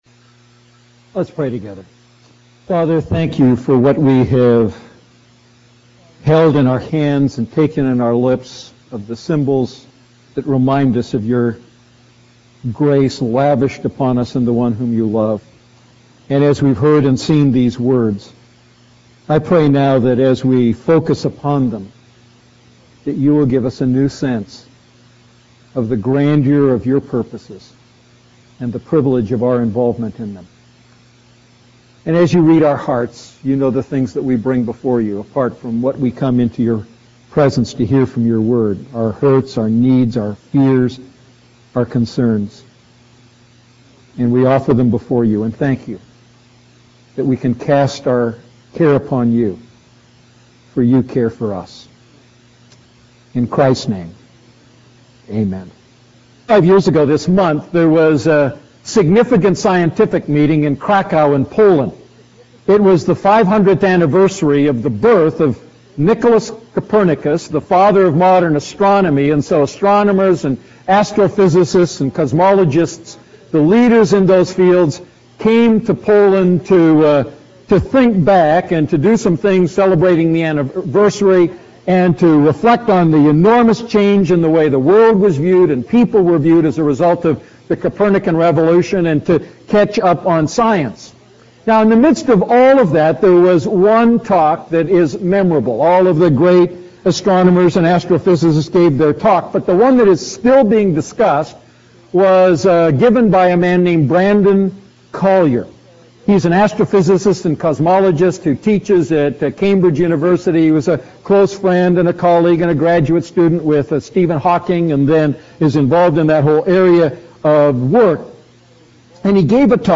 A message from the series "Ephesians Series."